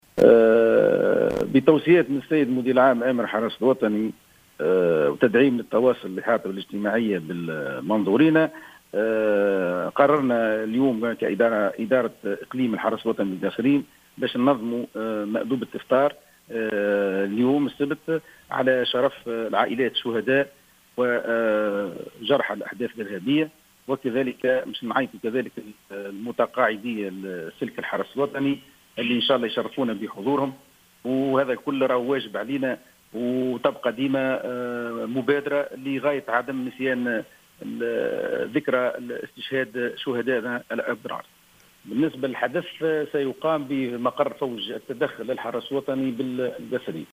وأكد مدير إقليم الحرس الوطني بالقصرين العميد، عادل الصماتي، في تصريح للجوهرة أف أم،أن هذه المبادرة التي ستلتئم بمقر فوج التدخل للحرس الوطني بالقصرين، عشية اليوم تأتي في إطار الحرص على تدعيم التواصل والإحاطة الاجتماعية بأعوان الحرس، وإحياء لذكرى استشهاد أبناء السلك في العمليات الإرهابية.